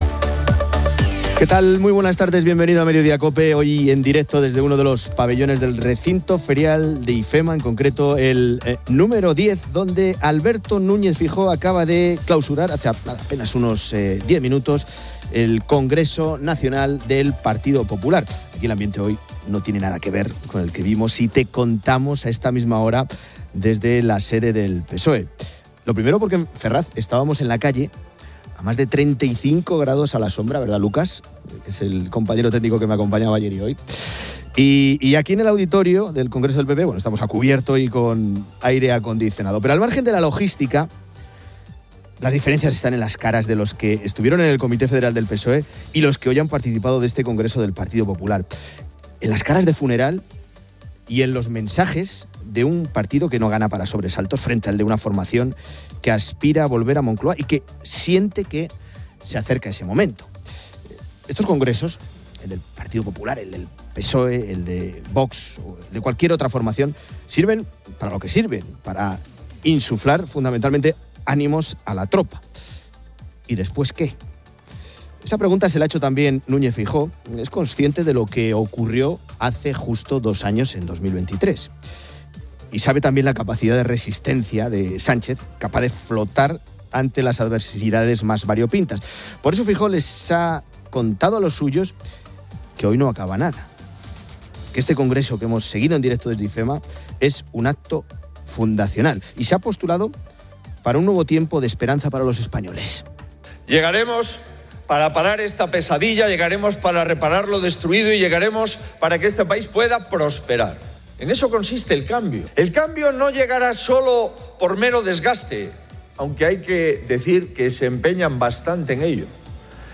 Hoy en directo desde uno de los pabellones del recinto ferial de Ifema, en concreto el número...